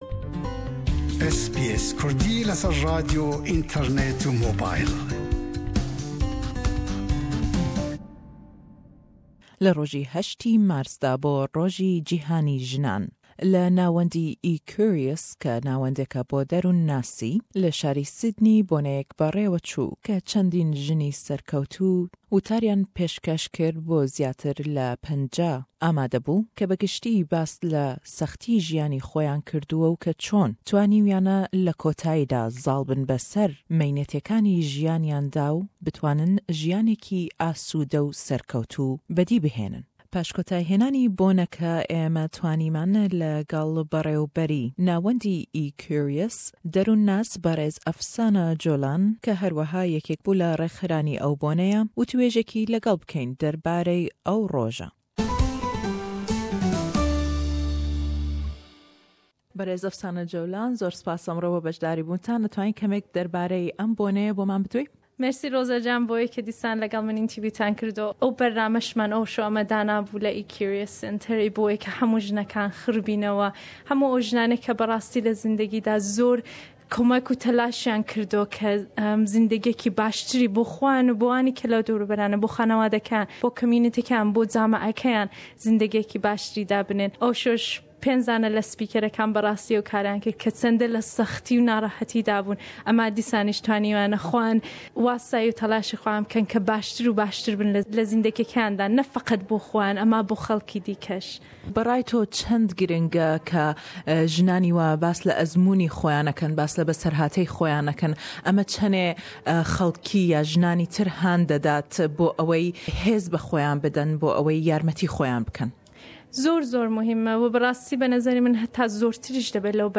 IWD event at Equrious centre/Sydney